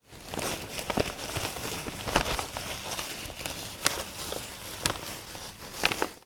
bandage_0.ogg